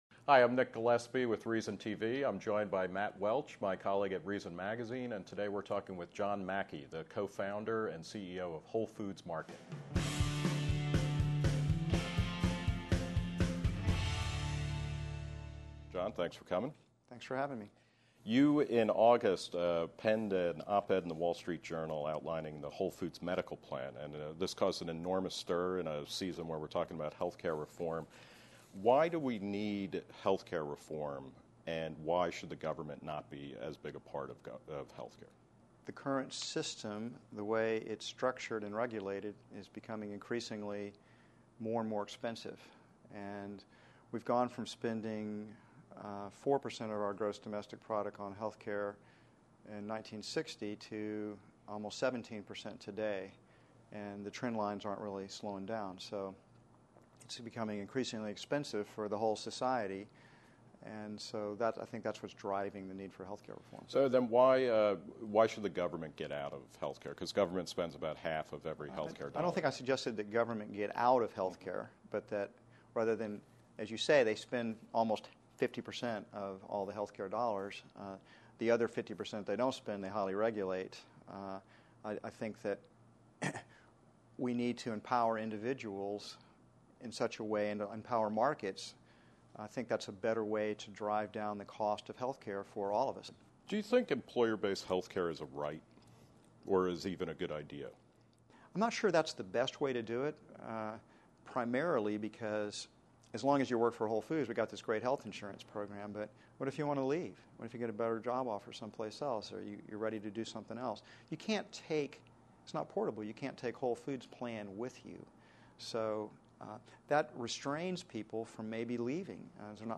This is an abridged version of an hour-long conversation with Mackey.